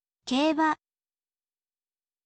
keiba